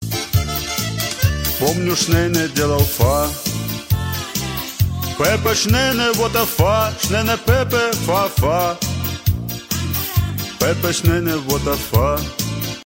современный шансон